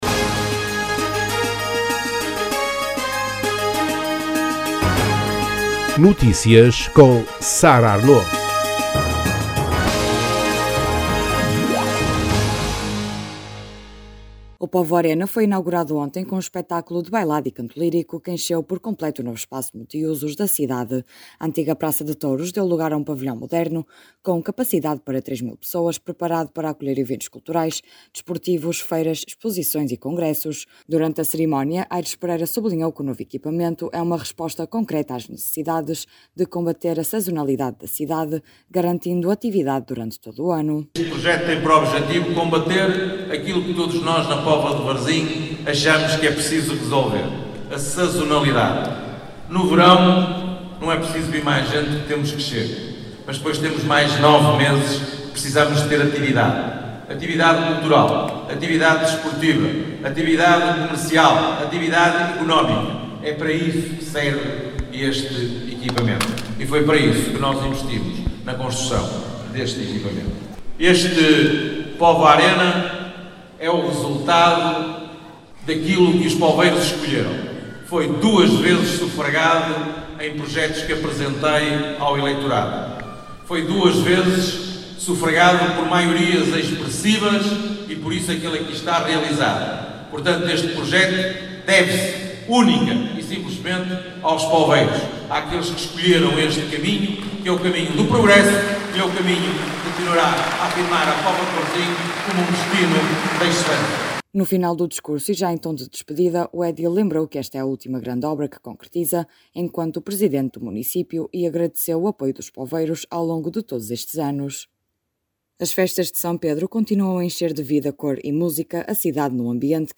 Notícias Regionais
Durante a cerimónia, Aires Pereira sublinhou que o novo equipamento é uma resposta concreta à necessidade de combater a sazonalidade da cidade, garantindo atividade durante todo o ano. No final do discurso, e já em tom de despedida, o edil lembrou que esta é a última grande obra que concretiza enquanto presidente do município e agradeceu o apoio dos poveiros ao longo de todos estes anos.